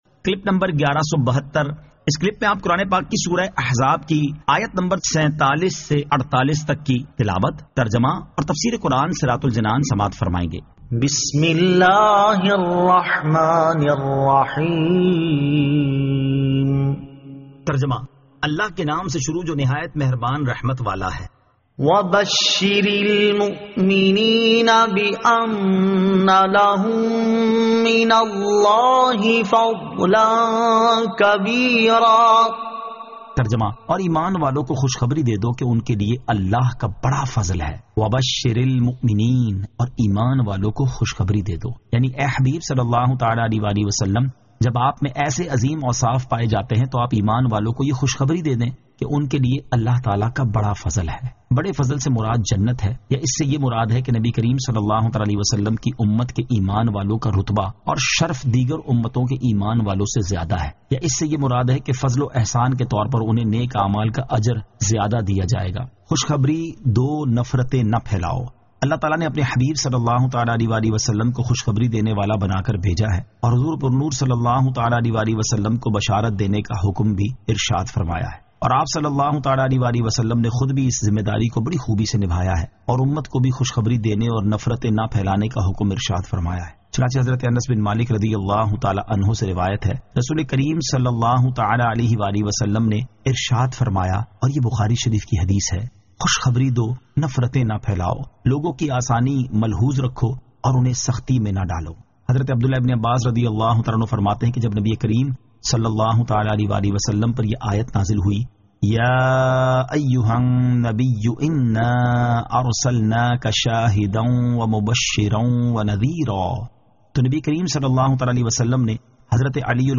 Surah Al-Ahzab 47 To 48 Tilawat , Tarjama , Tafseer